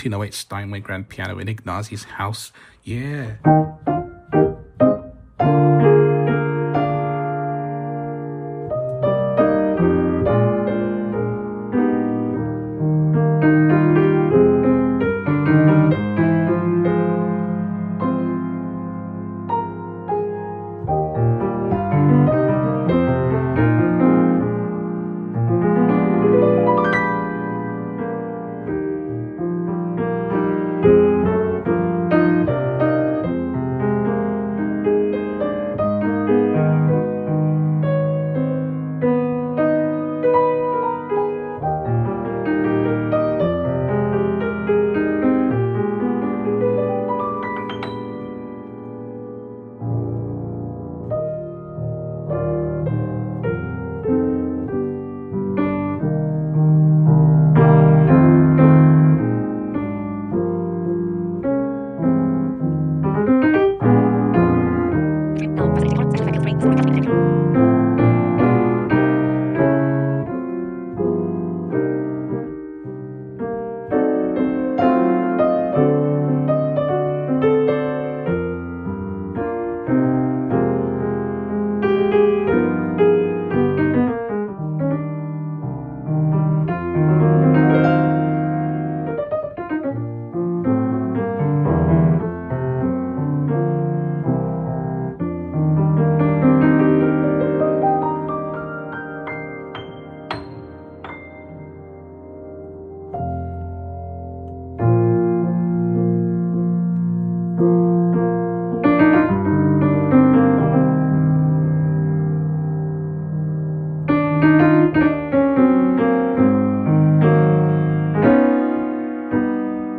sitting in Spain
Grand Piano